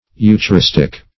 Eucharistic \Eu`cha*ris"tic\, Eucharistical \Eu`cha*ris"tic*al\,